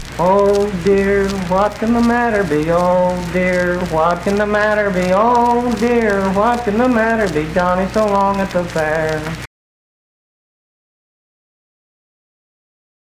Unaccompanied vocal performance
Verse-refrain 1(4).
Dance, Game, and Party Songs, Children's Songs
Voice (sung)